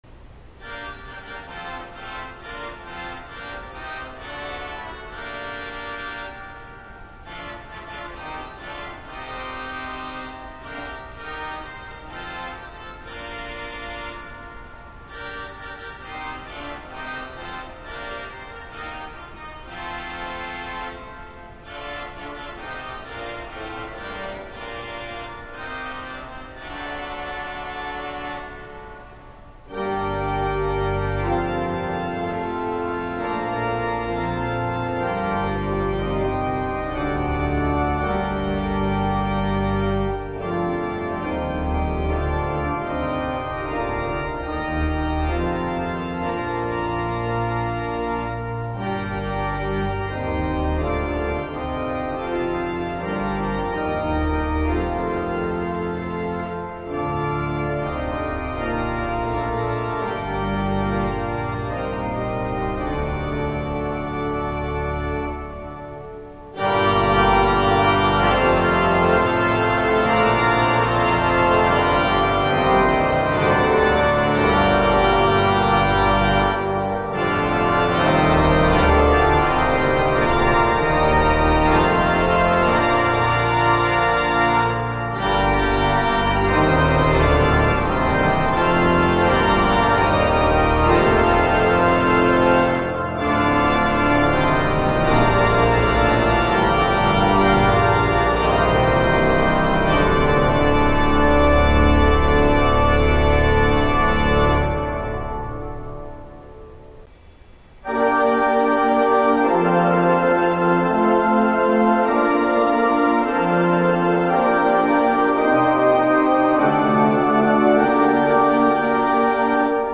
Voor de liefhebber, hier hoor je het tutti van het orgel in de Boardwalk Hall. Niet alleen de grote van het orgel, maar ook de indrukwekkende akoestiek maken het tot een belevenis voor het oor.
improvisatie op psalm 100. Ik kan me voorstellen dat het op zo'n groot orgel moeilijk is je ritme in bedwang te houden.